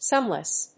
sún・less
音節sun･less発音記号・読み方sʌ́nləs